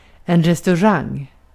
Uttal
• IPA: /rɛstɵˈraŋ/, /rɛstaʊ̯ˈraŋ/
• IPA: /ˌrɛst.(a)ɵˈraŋ/